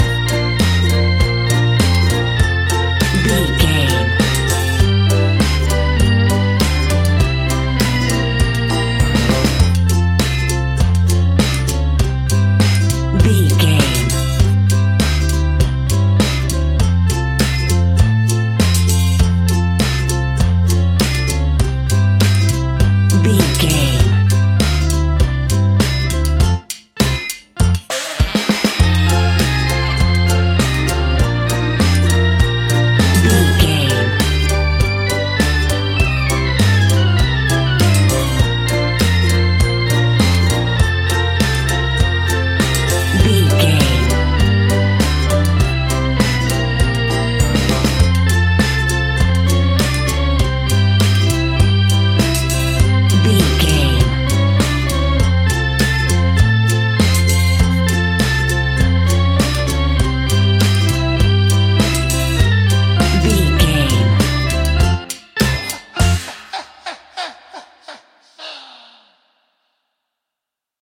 In-crescendo
Thriller
Aeolian/Minor
ominous
suspense
haunting
eerie
horror music
Horror Pads
horror piano
Horror Synths